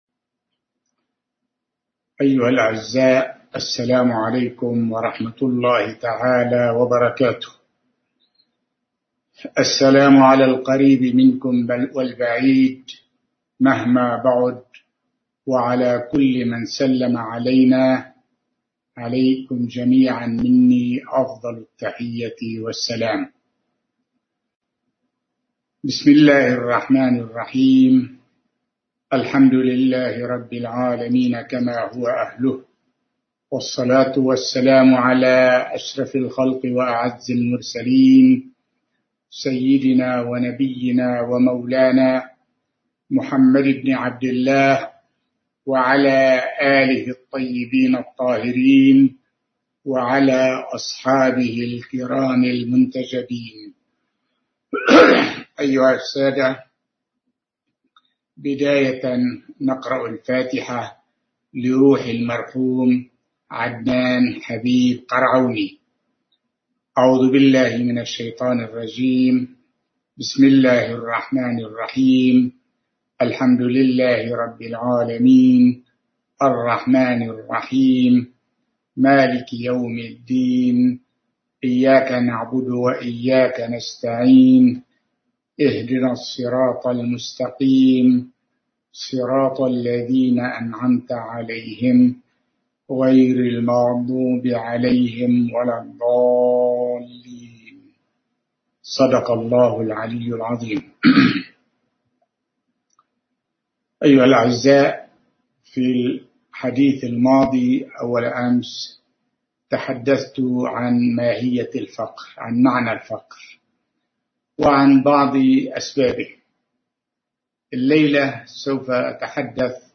سلسلة محاضرات
ألقاها في منزله في السنغال